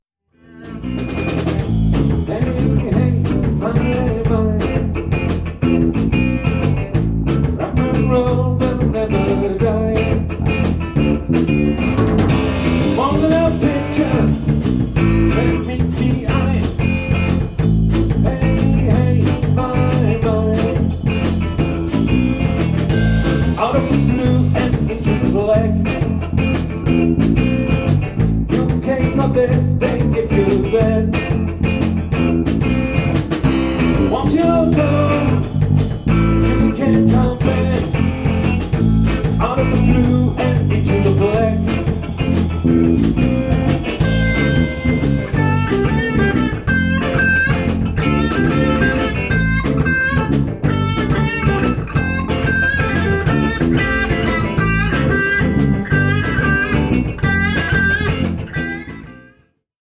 Der Sound ist für Musiker in einer Band immer eine zentrale Frage, heute Abend kam deshalb bei der Bandprobe von das für Puristen einzig zulässige Effektgerät zum Einsatz, ... ein Ventilator!